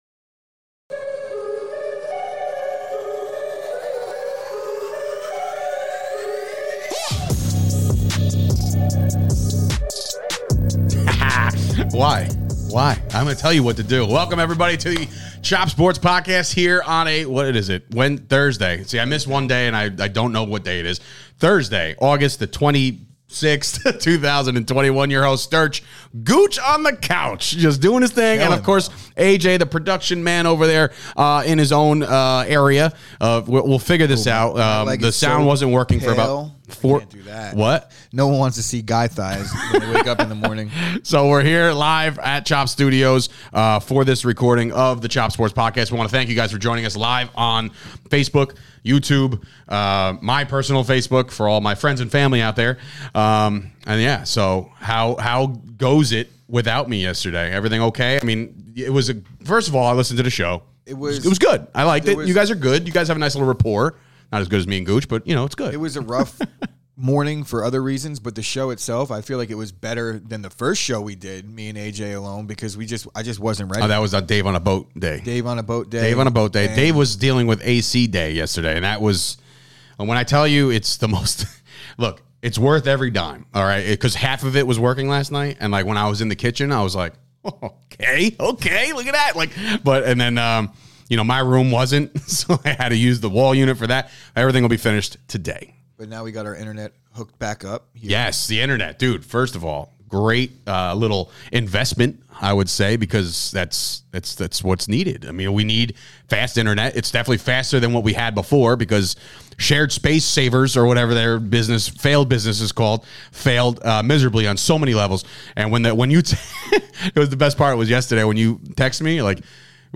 the boys are reunited for a new set for the Chop Sports Podcast LIVE Show.